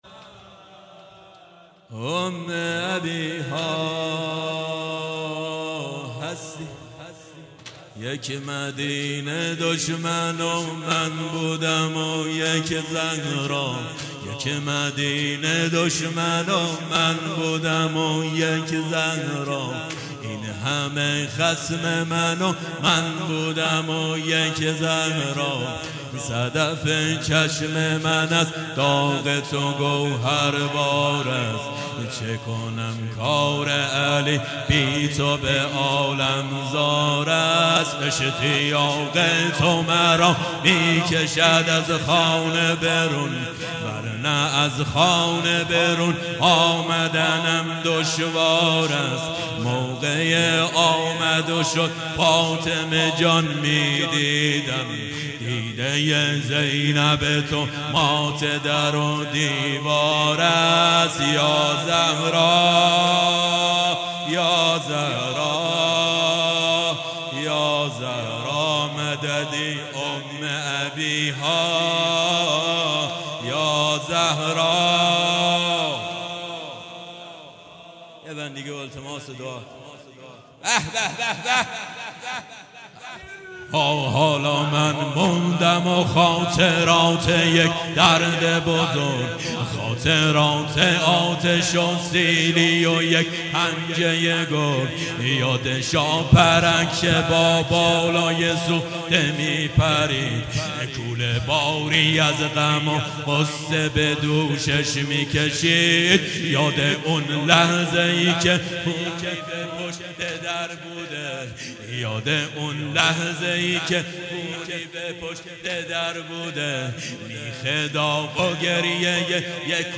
سنگین شلاقی)3.mp3 متاسفانه مرورگر شما، قابیلت پخش فایل های صوتی تصویری را در قالب HTML5 دارا نمی باشد.